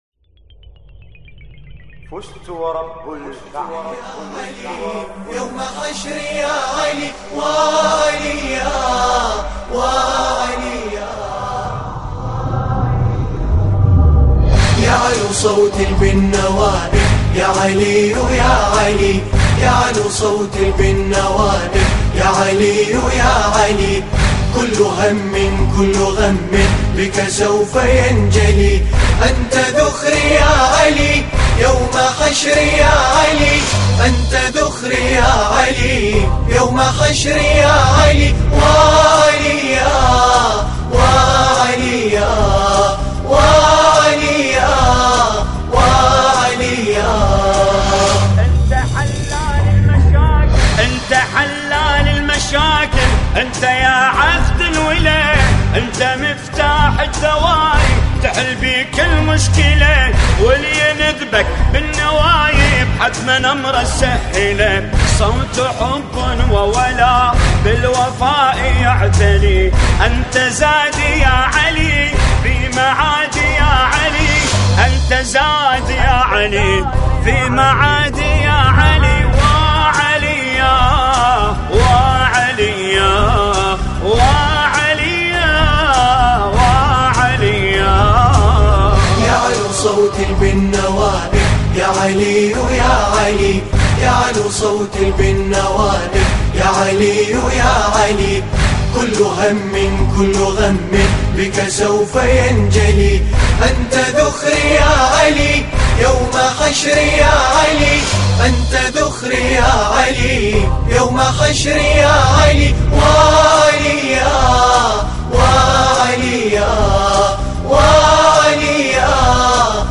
مراثي الامام علي (ع)